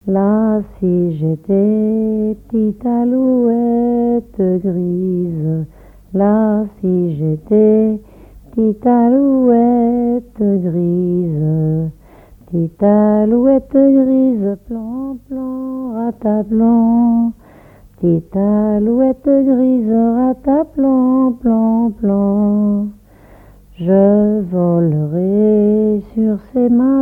Genre laisse
collecte en Vendée
Pièce musicale inédite